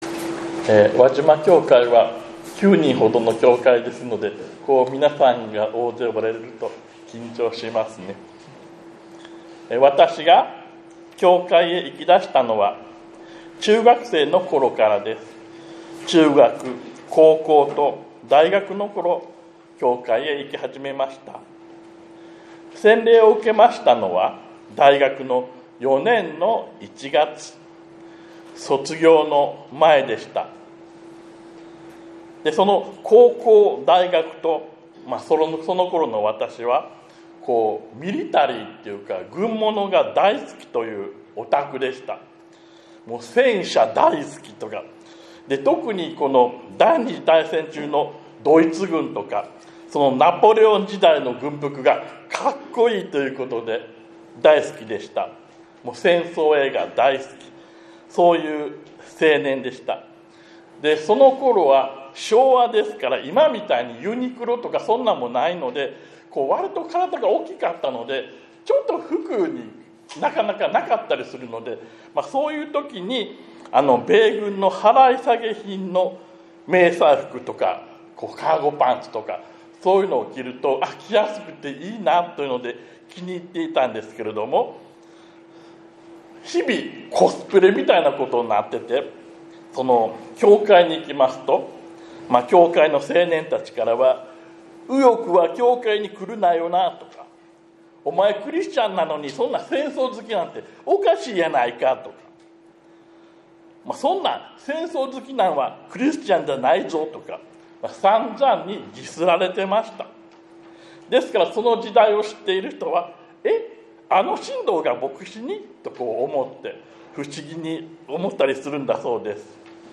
主日礼拝 サムエル記上１６章４節～１３節 ルカによる福音書１章６８節～６９節